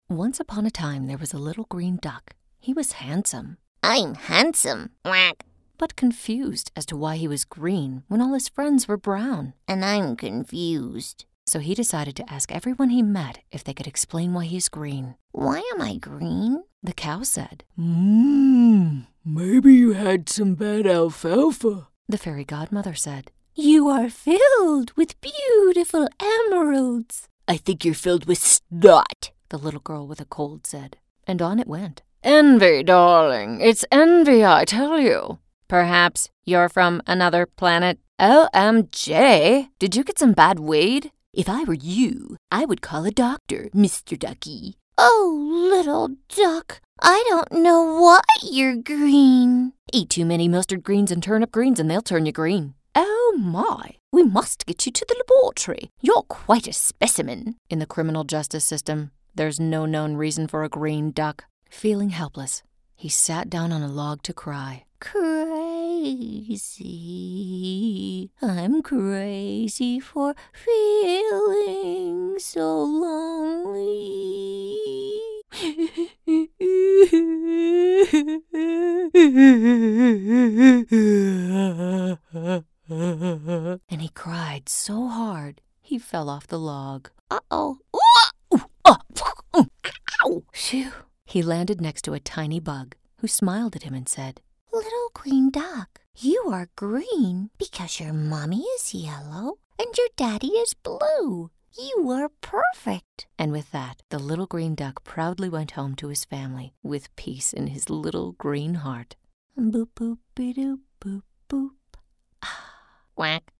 From conversational girl-next-door to straight announcer delivery, I am happy to work with you to deliver the best style for your company and project.
middle west
Sprechprobe: Sonstiges (Muttersprache):